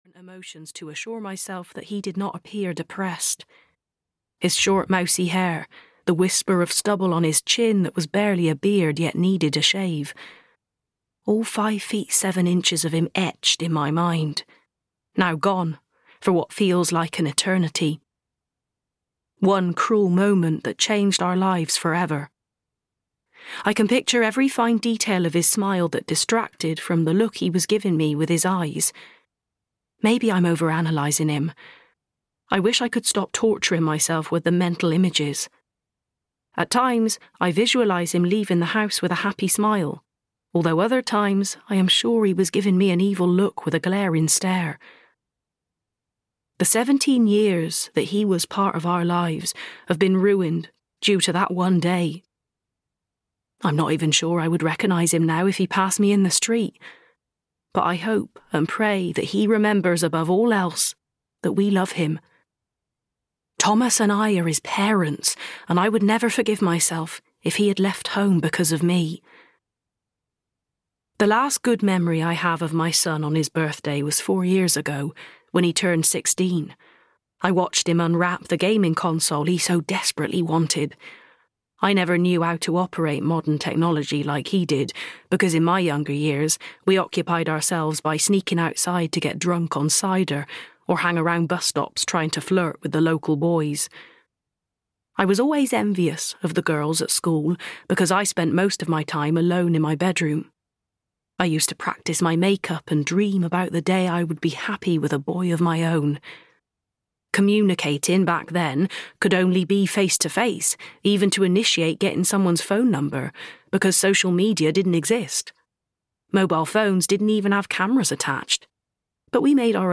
Mummy's Boy (EN) audiokniha
Ukázka z knihy